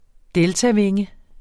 Udtale [ ˈdεlta- ]